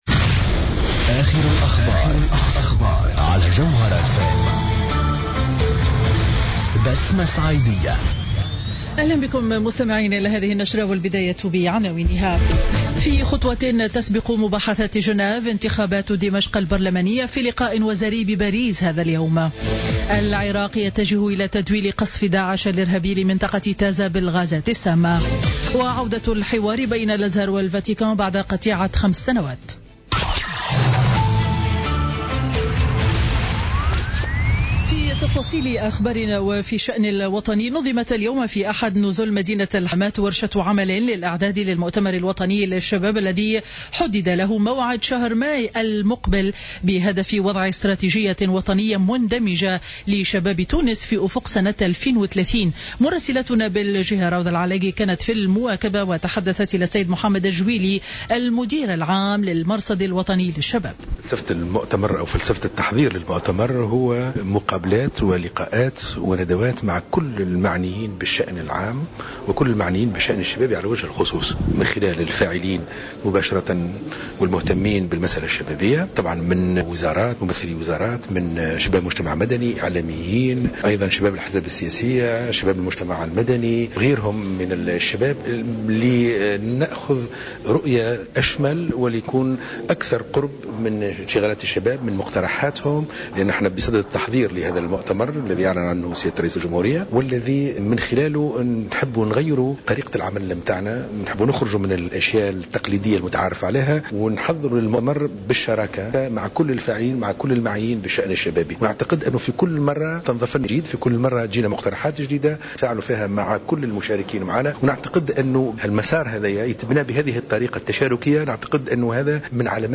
نشرة أخبار منتصف النهار ليوم الأحد 13 مارس 2016